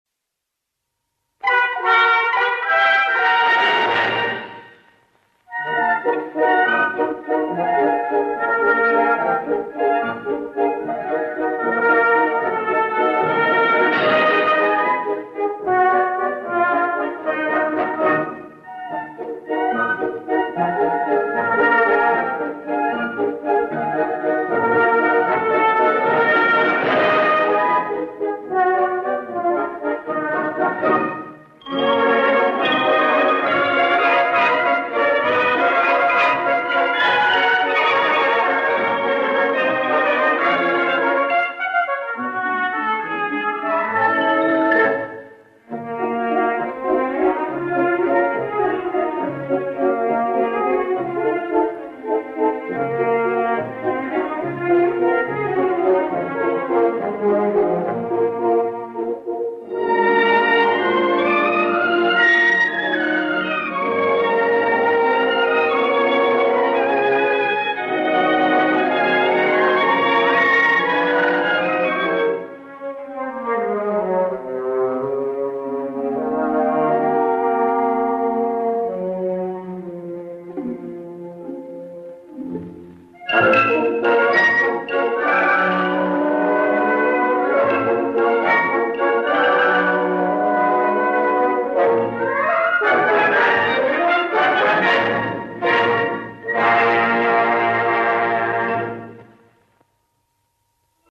музыка для танцев